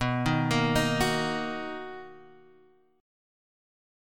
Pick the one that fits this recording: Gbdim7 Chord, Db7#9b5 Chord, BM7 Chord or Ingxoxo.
BM7 Chord